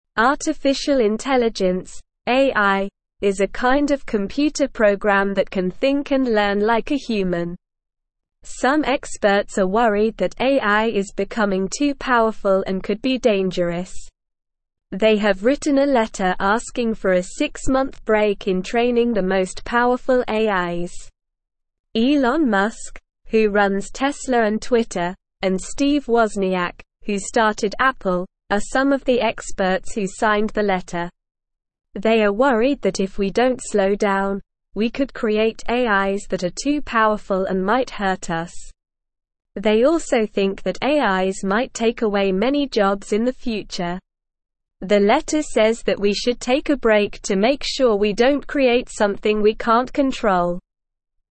Slow
English-Newsroom-Beginner-SLOW-Reading-Experts-Ask-for-Break-in-AI-Training.mp3